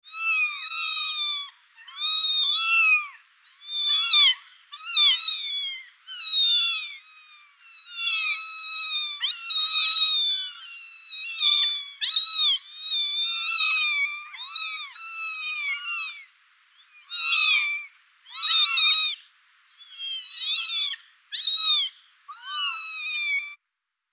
Звуки котов
Одинокое мяуканье